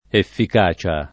efficacia [ effik #© a ] s. f.